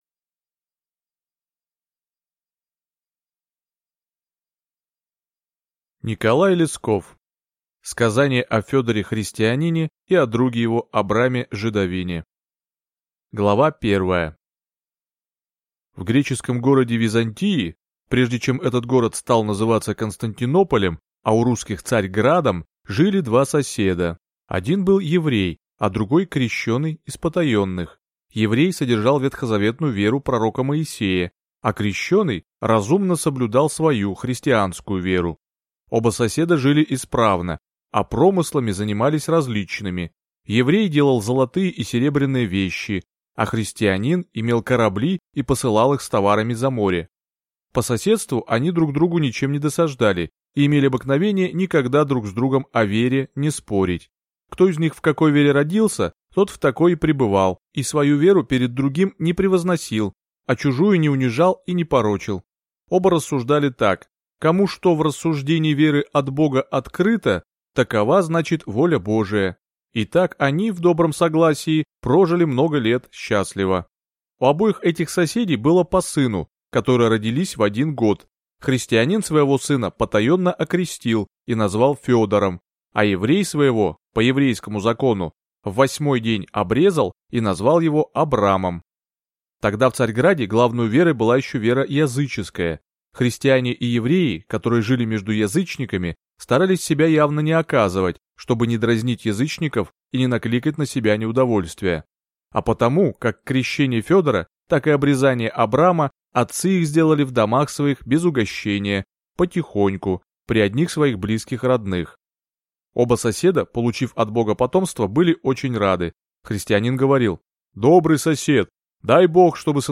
Аудиокнига Сказание о Федоре-христианине и о друге его Абраме-жидовине | Библиотека аудиокниг